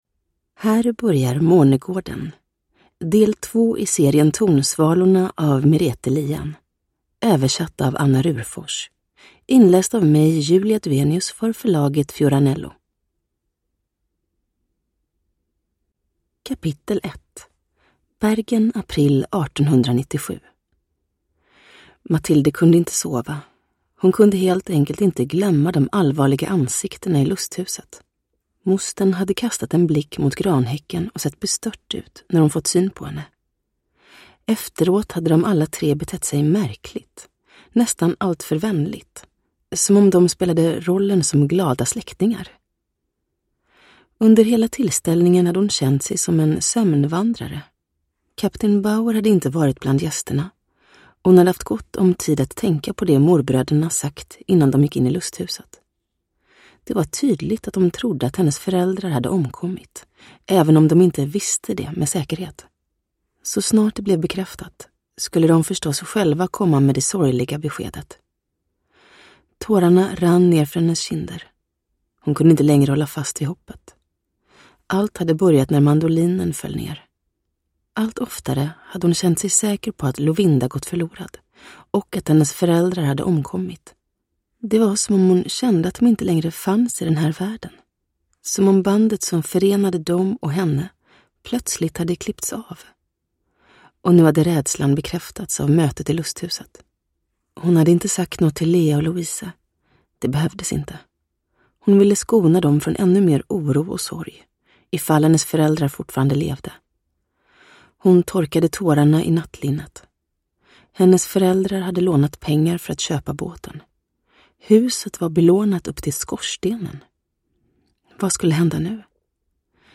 Månegården (ljudbok) av Merete Lien